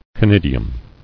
[co·nid·i·um]